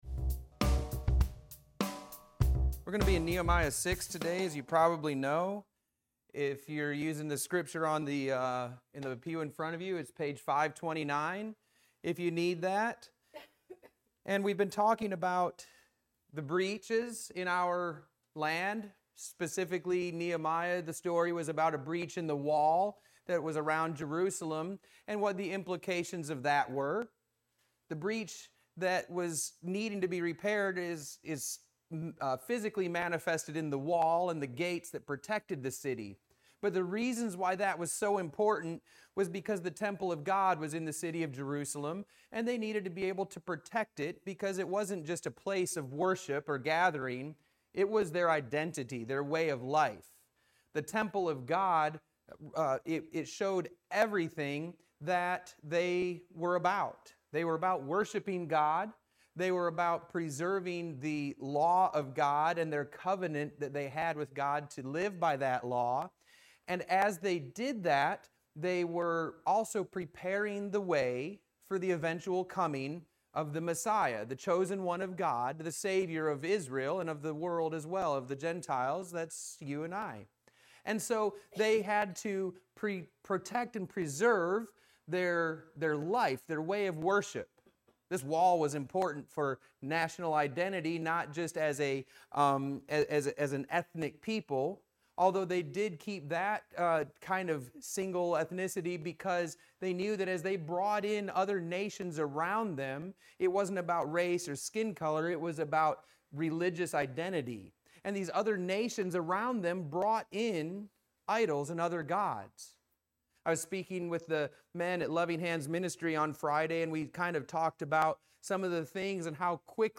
Religion Christianity